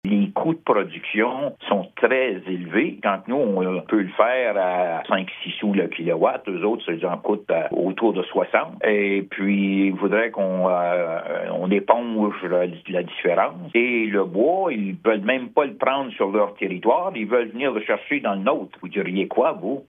Le député de la circonscription provinciale de Gatineau, Robert Bussière, expose aussi son point de vue sur ce dossier :